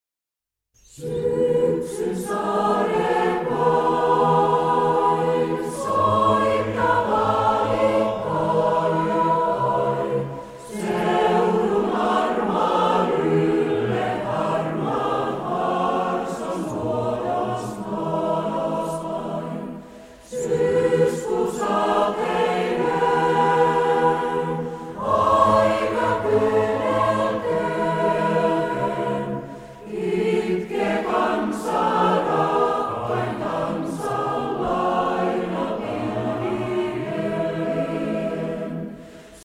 Puolalainen balladi